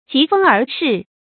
注音：ㄐㄧˊ ㄈㄥ ㄦˊ ㄕㄧˋ
及鋒而試的讀法